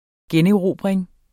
Udtale [ ˈgεneˌʁoˀbʁεŋ ]